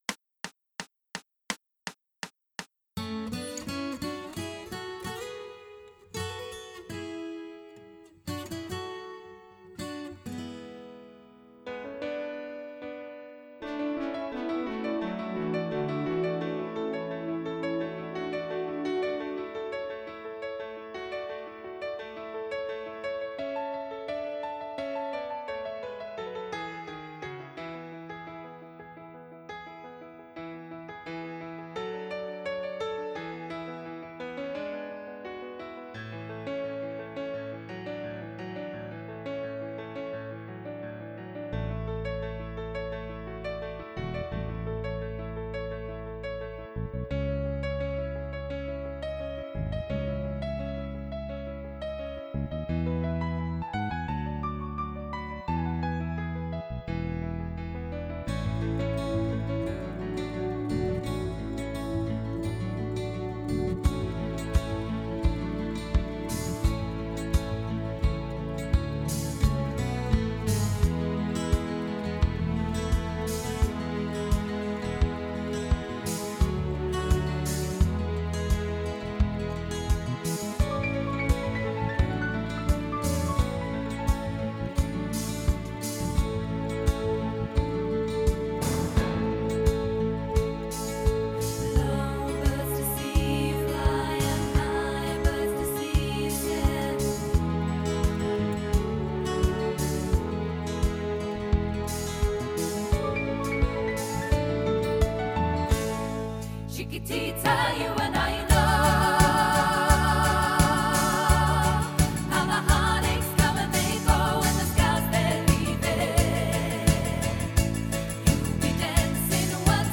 4 VOCALS